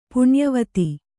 ♪ puṇyavati